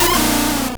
Cri d'Insolourdo dans Pokémon Or et Argent.